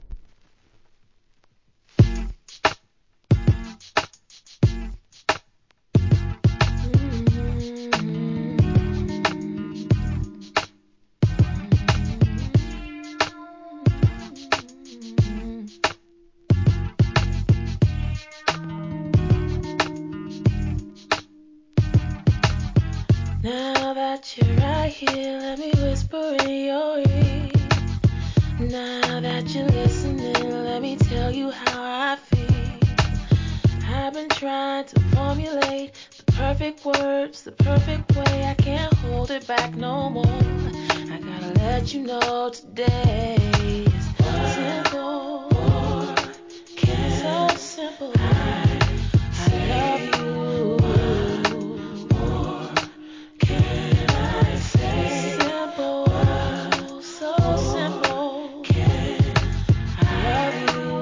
HIP HOP/R&B
シンプルな打ち込みに流れるようなメロディーを配し、ハスキーな魅惑のヴォーカルで聴かせます!!